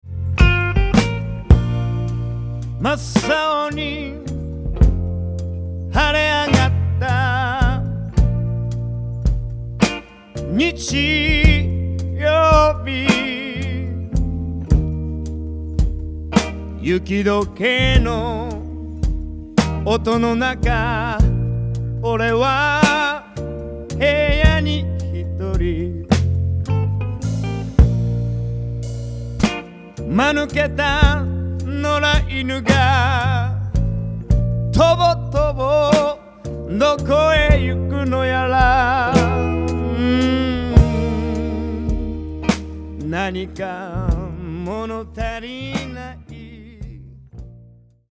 ジャンル：ロック/ブルース